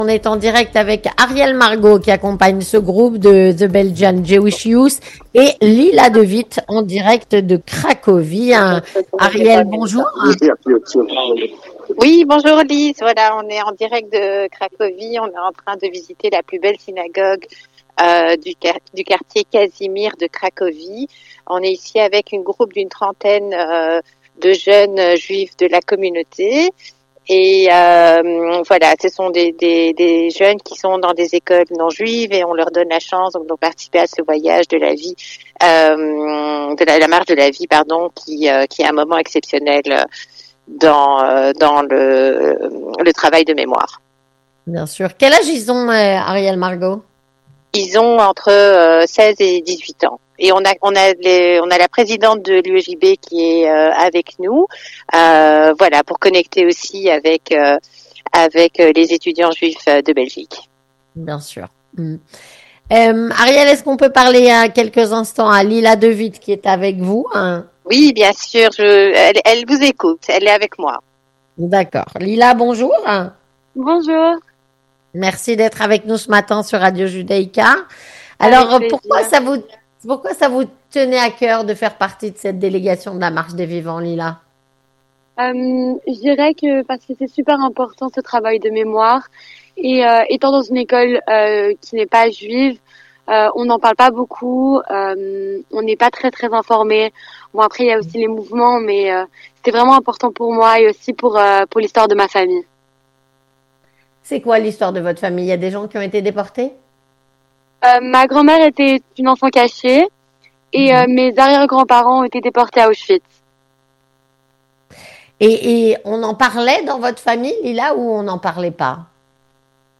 3. Témoignage
Elles étaient avec nous, en direct de Cracovie, pendant le journal.